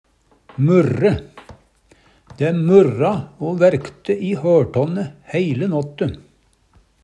murre - Numedalsmål (en-US)